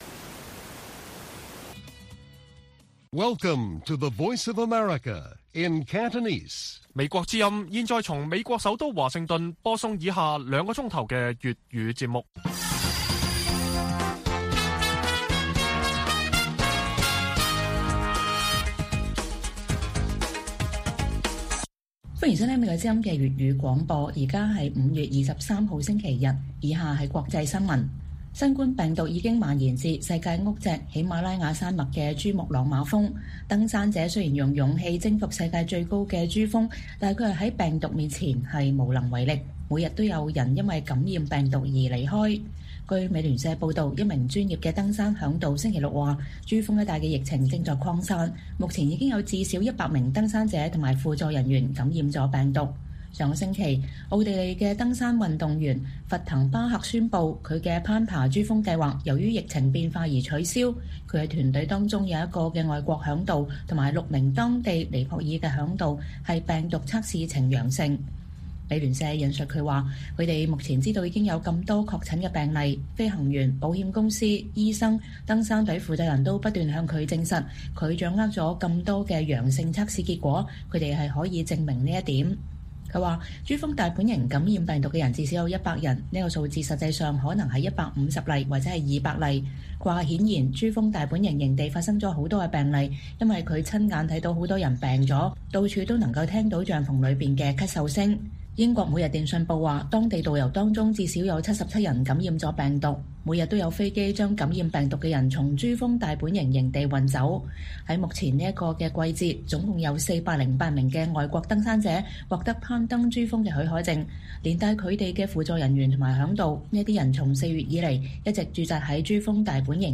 粵語新聞 晚上9-10點：新冠疫情肆虐世界屋脊珠峰